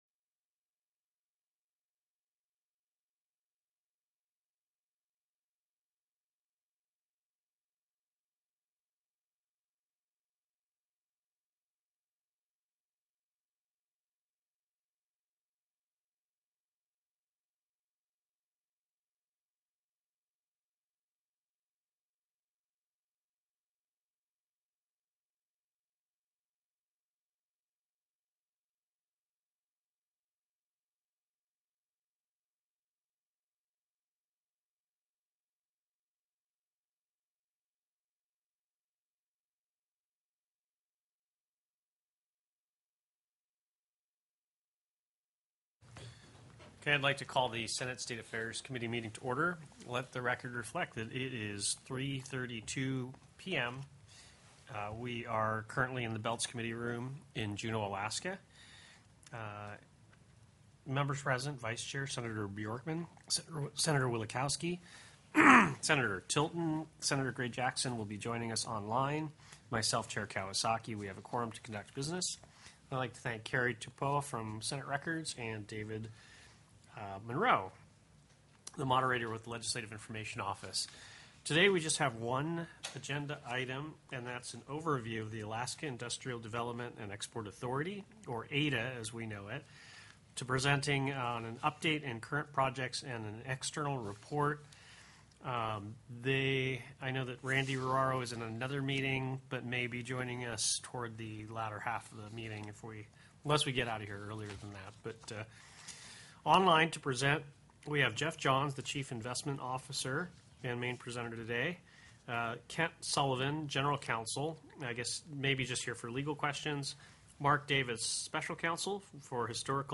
The audio recordings are captured by our records offices as the official record of the meeting and will have more accurate timestamps.
Senator Gray-Jackson joined via teleconference.